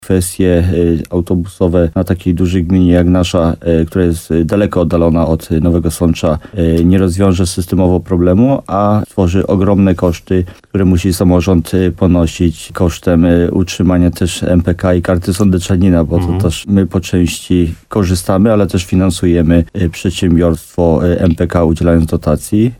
Wójt Jacek Migacz wyjaśnia, że wprowadzenie dodatkowego autobusu byłoby kosztowne.